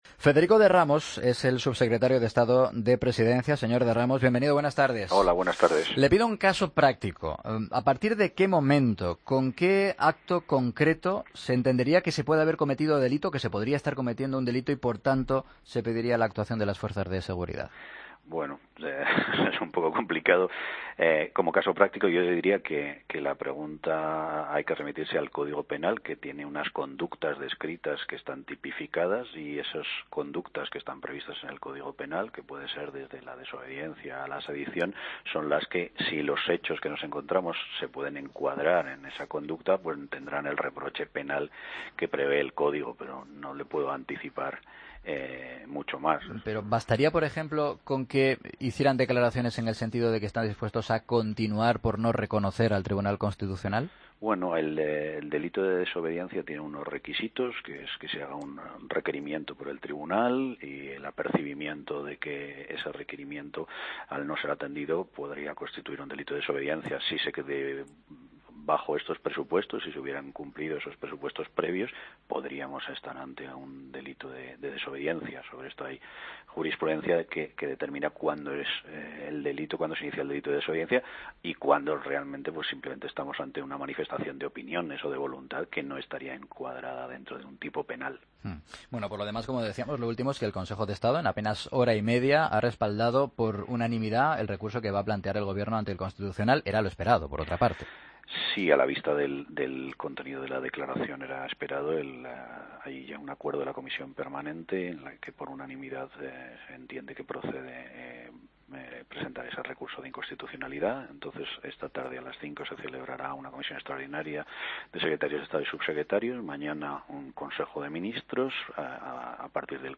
AUDIO: Escucha al subsecretario de Estado de Presidencia en 'Mediodía COPE'